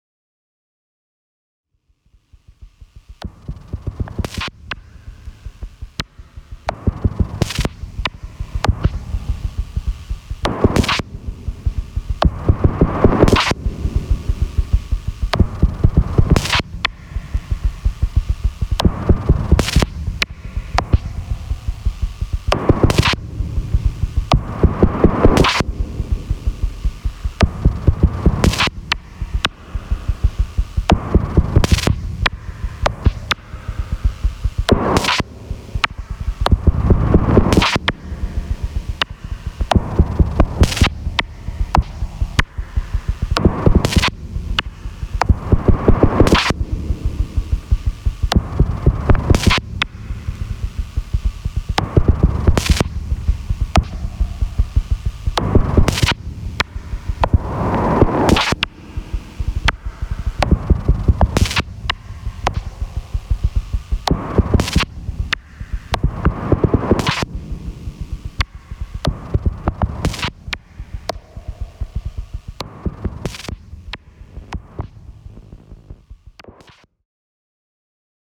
Mixing with very high levels of distortion on broken mixers from the 80’s is hard.